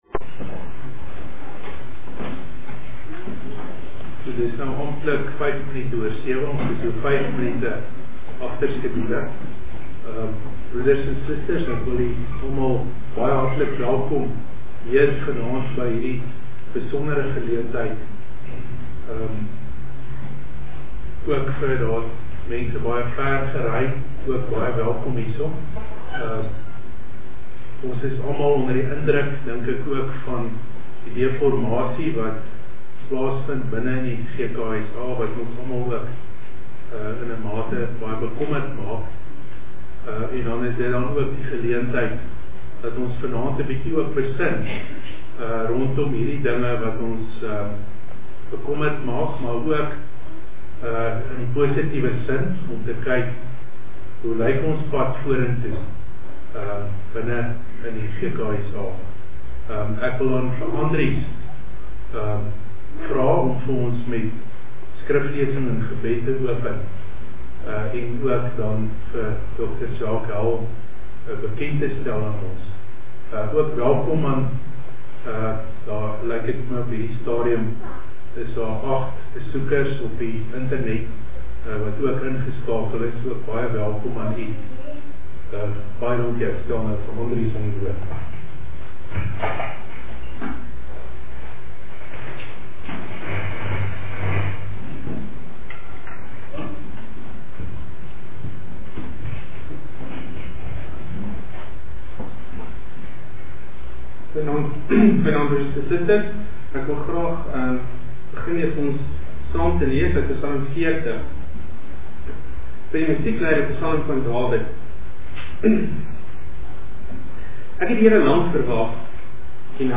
Die lesing is gehou by die GK Bet-el in Pretoria, 20 April 2012.